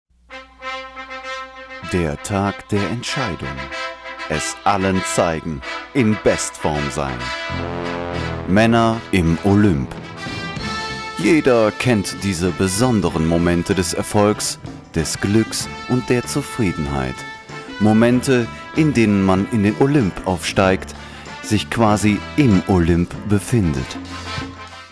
Stimmalter: 30-45 Stimmfarbe: weich, seriös, musikalisch, Sonor mit großer Präsenz Genre: Feature, Moderation, Nachrichten, Sachtext, Off-Text Dokumentationen, Telefonansagen, Werbung
Deutscher Sprecher, Musiker.
Sprechprobe: eLearning (Muttersprache):